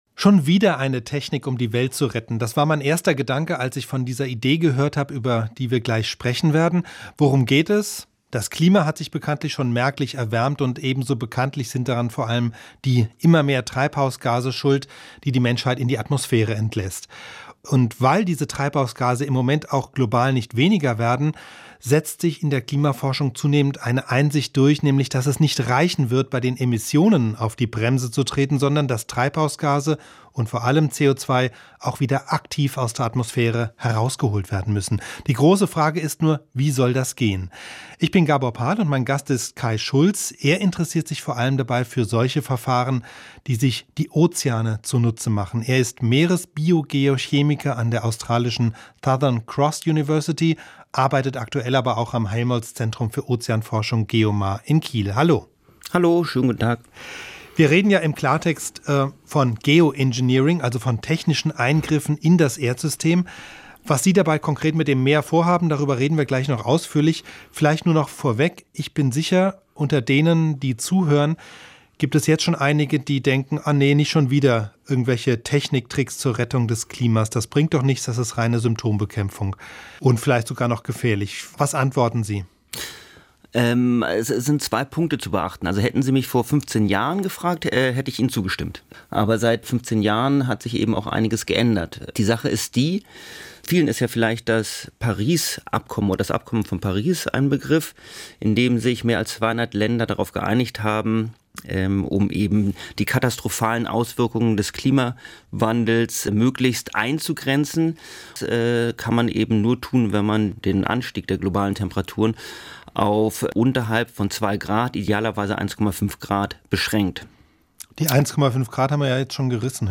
im Gespräch mit dem Ozeanforscher